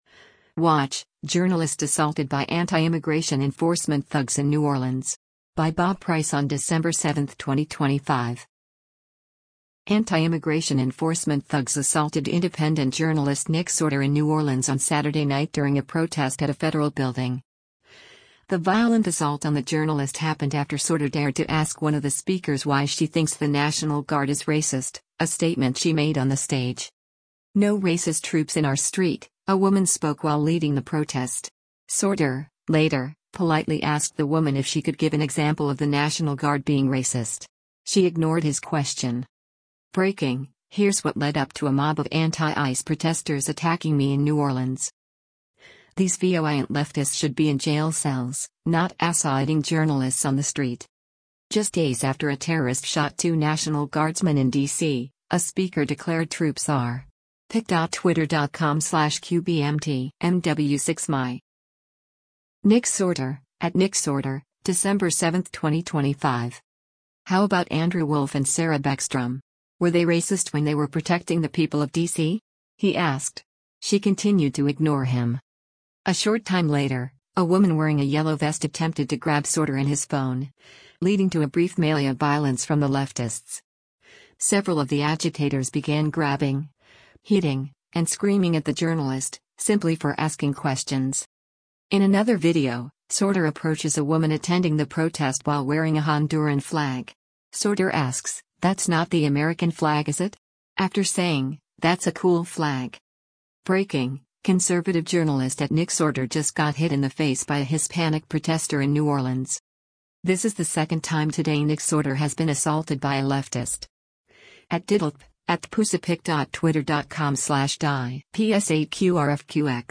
A protester waves a flag in a pouring rain during a demonstration against an impending Cus
“No racist troops in our street,” a woman spoke while leading the protest.
Several of the agitators began grabbing, hitting, and screaming at the journalist, simply for asking questions.